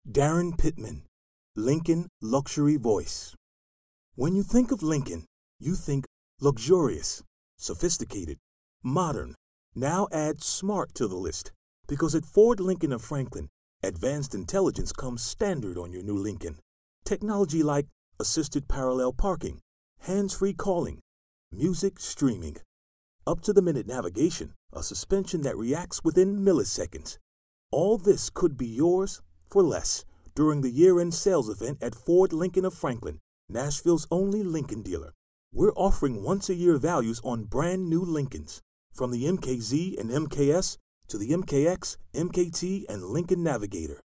The ceiling is fully covered but the walls are about 1/2 done. The attached read was done in the finished half of the space speaking into my mic which I placed in the V shape of the corner.
I use a laptop, with protools and an at4040 mic.